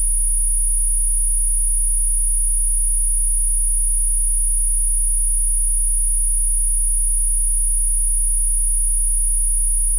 Para evaluar el circuito de medición de fase y los filtros, hice un programa que generar archivos tipo RIFF (WAV), con la señal compuesta del VOR simulada (30 Hz básicos + 9960 Hz modulados en frecuencia).
Se genera la señal con 8 bits de resolución y monoaural.
Señal de vor sin desfasaje (Sur)